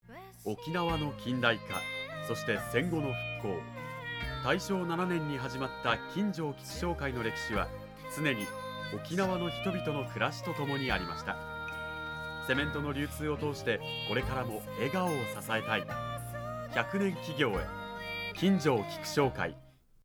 ラジオCM